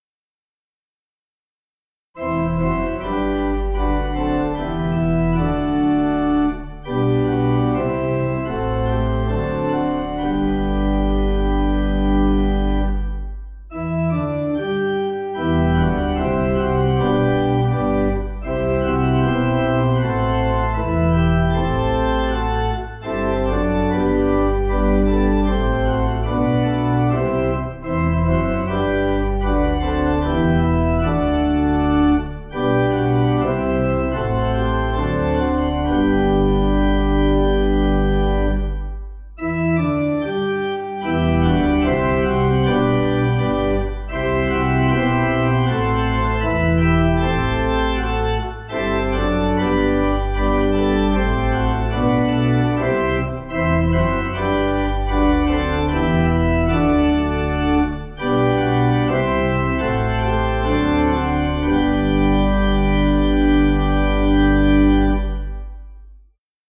Finnish Melody
Organ